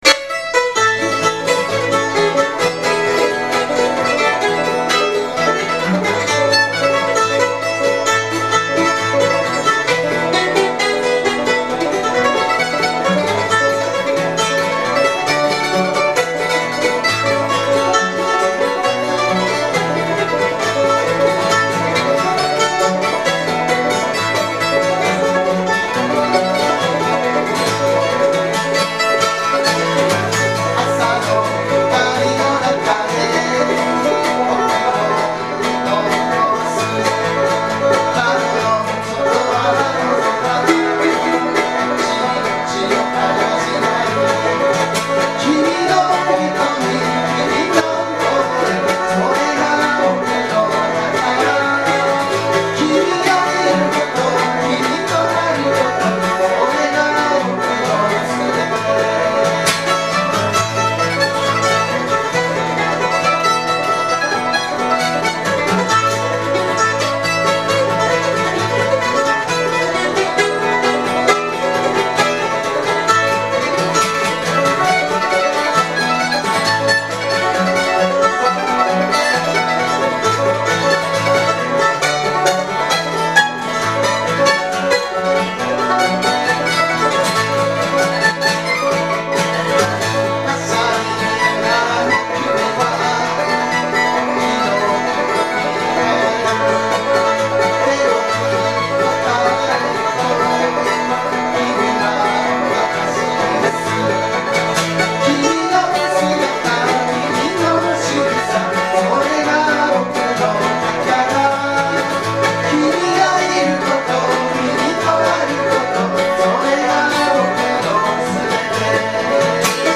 Bluegrass style Folk group
Key of A
マンドリンがもっとも得意とするAのキーで演奏しています。
企画名: Studio Live III
録音場所: 与野本町BIG ECHO
コーラス、マンドリン
コーラス、バンジョー
ボーカル、ギター